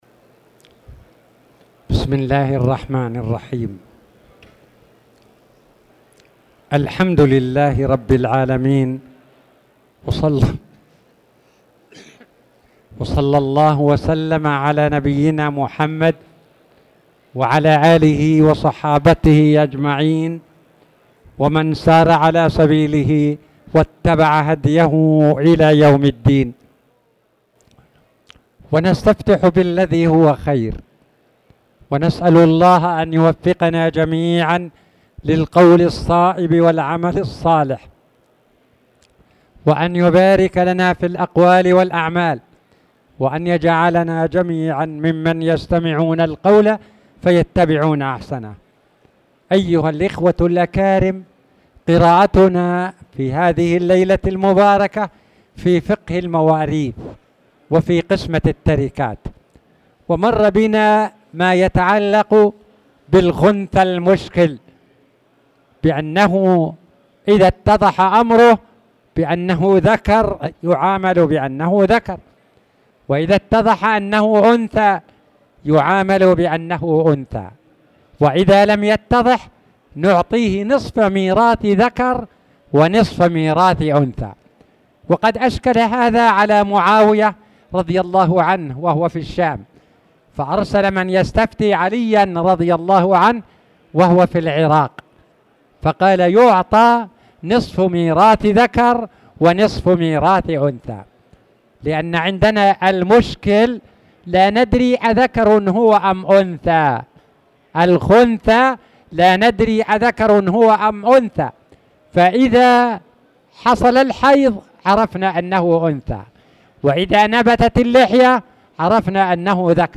تاريخ النشر ١٢ ذو القعدة ١٤٣٧ هـ المكان: المسجد الحرام الشيخ